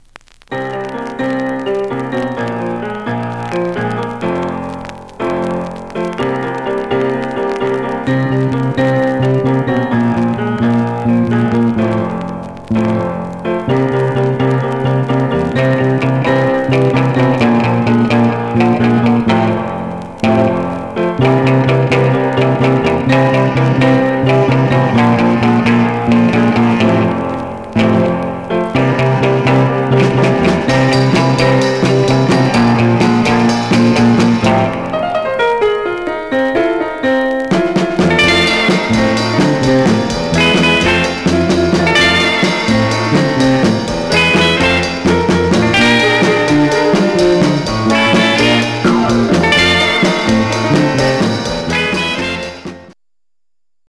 Surface noise/wear
Mono
R & R Instrumental